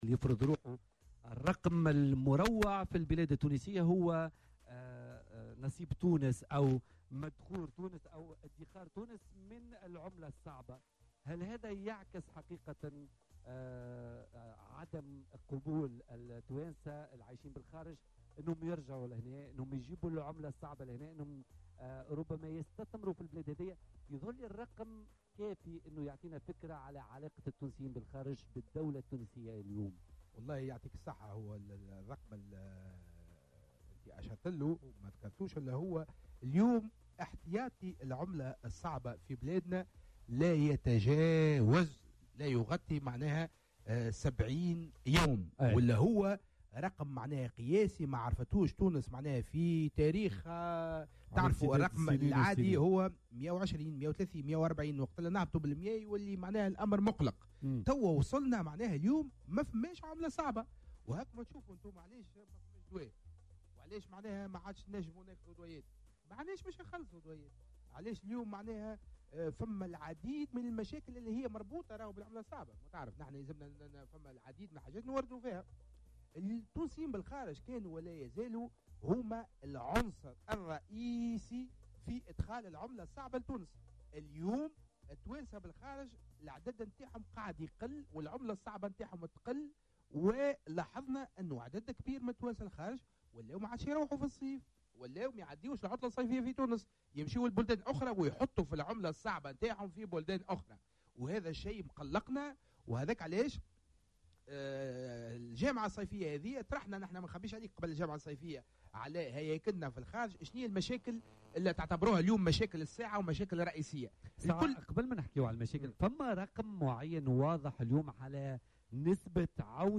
وأكد النائب رياض جعيدان ضيف بولتيكا أن احتياطي العملة الصعبة لايغطي 70 يوما من التوريد وهو رقم قياسي لم تشهده تونس في تاريخها إلى حد اليوم.